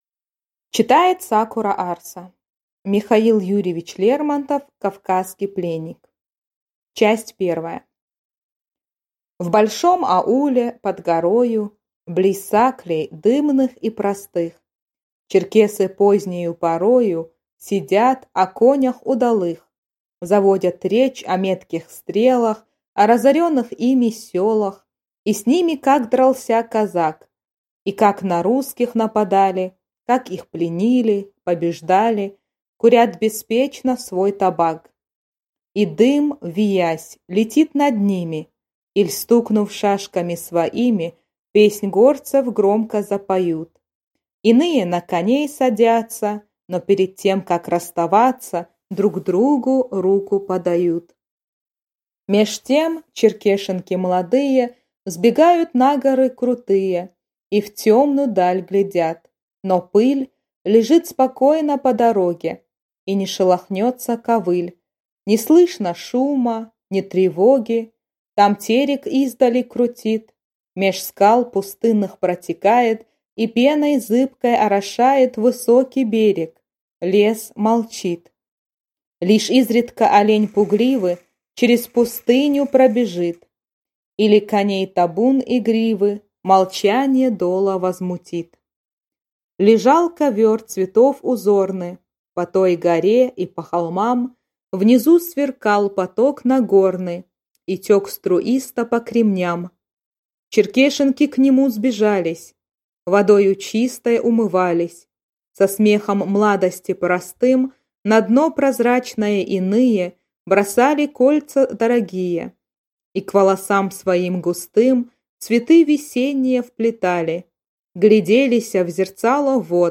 Аудиокнига Кавказский пленник